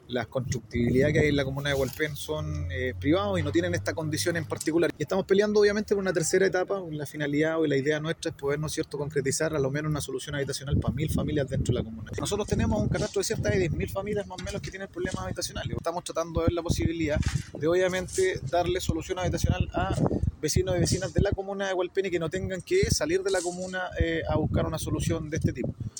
El alcalde de Hualpén, Miguel Rivera, hizo foco en la importancia que tienen estas obras, teniendo en cuenta el grave problema habitacional que afecta a la población de la comuna y amplió la posibilidad de concretar una tercera etapa para mitigar esta problemática.
cuna-alcalde-hualpen.mp3